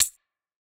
Index of /musicradar/ultimate-hihat-samples/Hits/ElectroHat B
UHH_ElectroHatB_Hit-18.wav